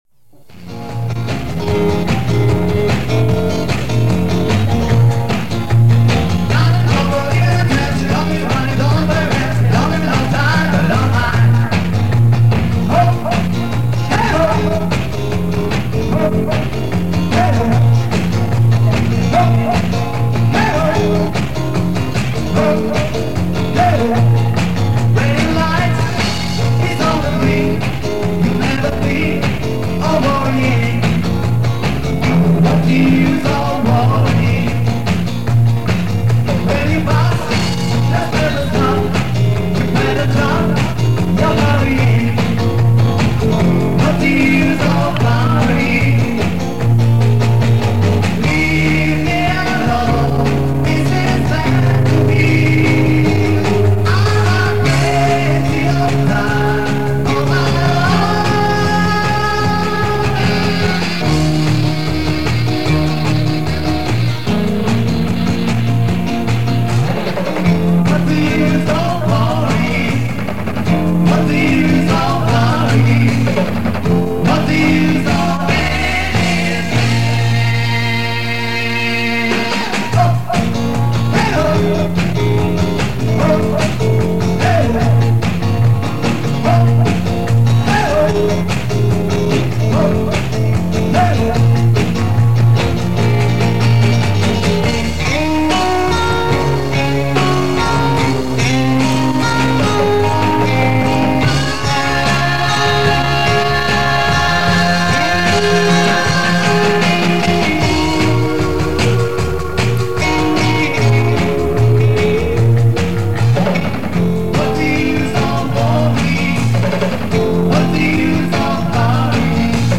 концерт 1974 г